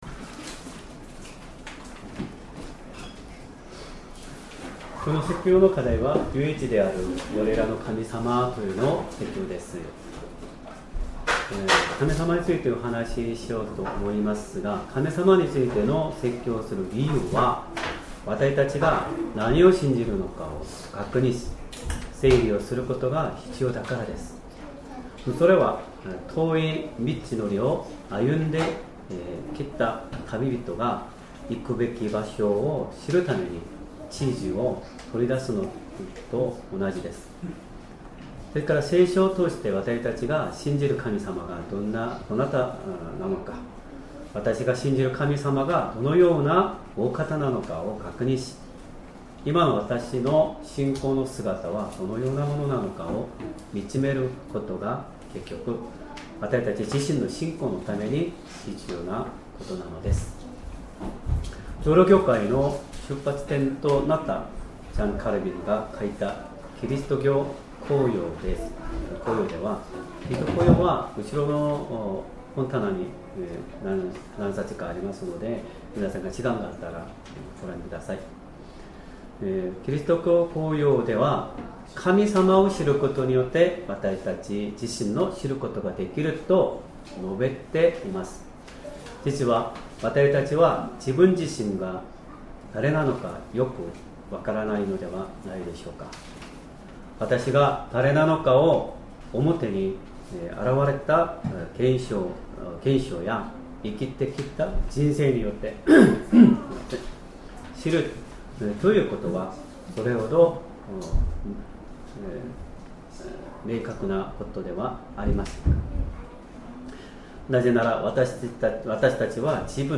Sermon
2025年9月21日 主日礼拝 説教 「 唯一である我らの神様」 聖書 申命記 6章 4-5節 6:4 聞け、イスラエルよ。